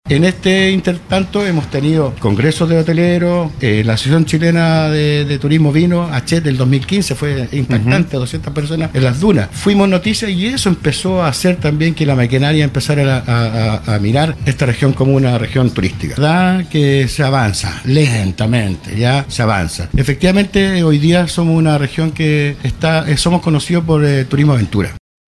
pero refrescante entrevista que representa un proyecto novedoso que se realiza con el apoyo de Hotel y Casino Antay